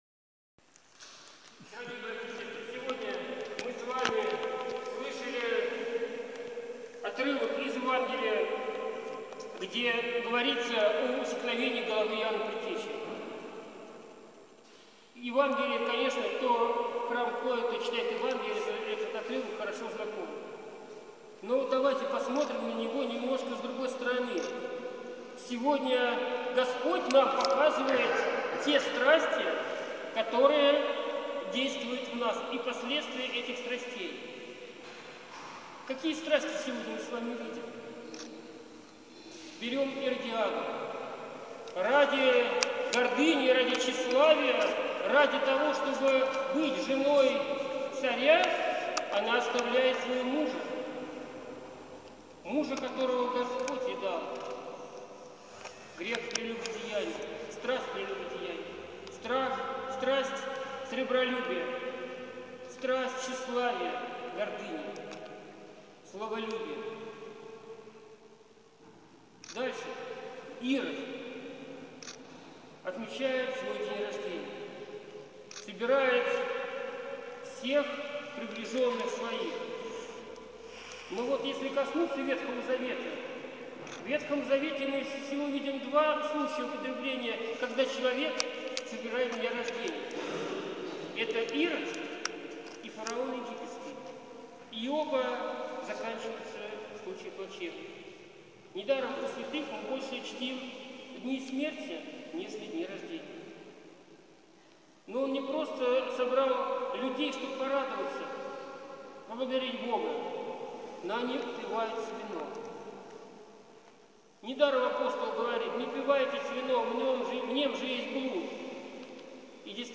Священнослужитель обратился к верующим с пастырским словом, посвященным празднику.